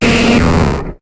Cri de Limagma dans Pokémon HOME.